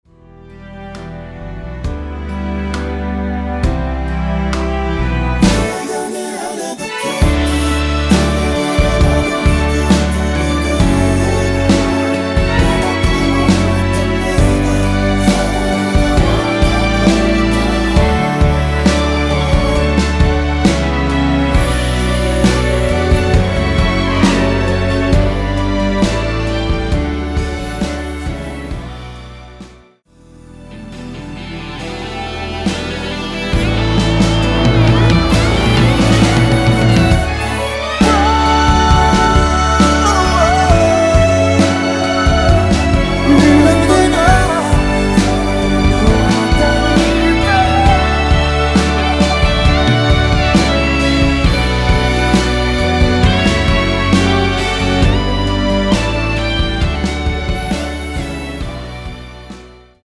원키에서(-1)내린 코러스 포함된 MR입니다.
◈ 곡명 옆 (-1)은 반음 내림, (+1)은 반음 올림 입니다.
앞부분30초, 뒷부분30초씩 편집해서 올려 드리고 있습니다.